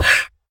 Minecraft Version Minecraft Version 1.21.5 Latest Release | Latest Snapshot 1.21.5 / assets / minecraft / sounds / mob / horse / donkey / hit1.ogg Compare With Compare With Latest Release | Latest Snapshot